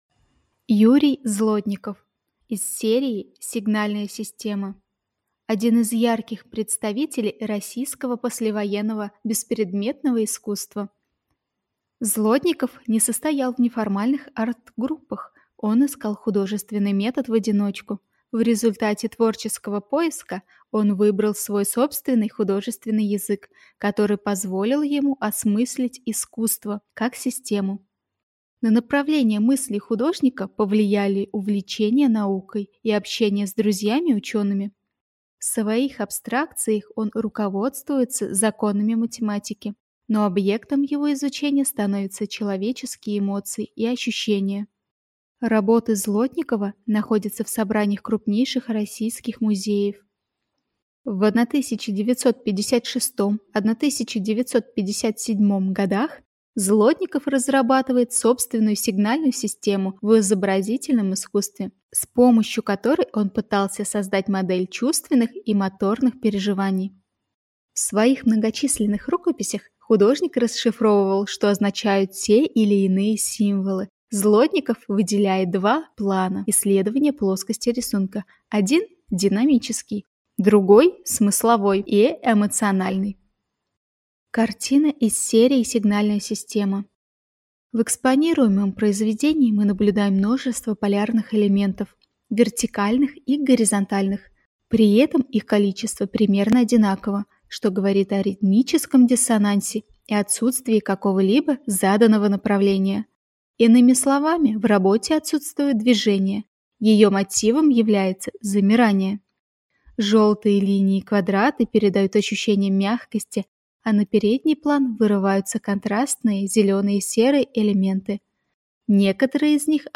Тифлокомментарий к картинам Юрия Злотникова из серии "Сигнальная система"